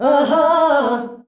AHAAH E.wav